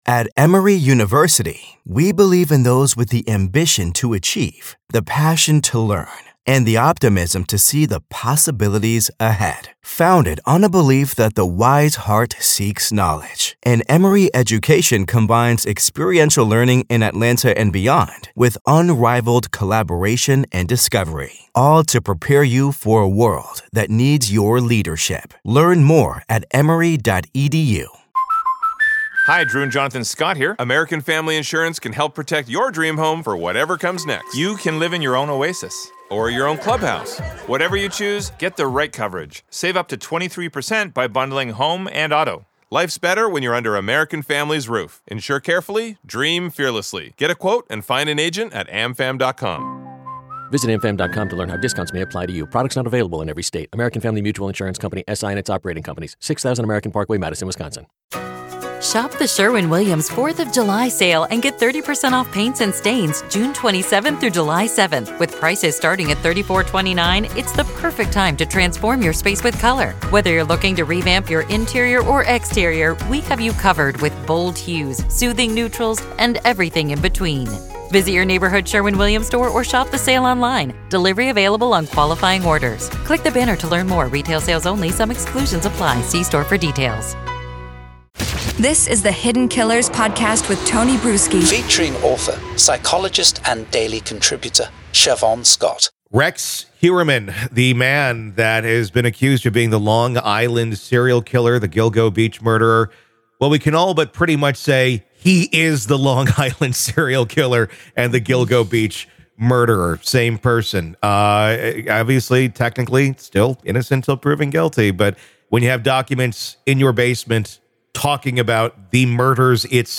The conversation underscores the difficulty of detecting such predators and the role of modern forensic technology in catching them.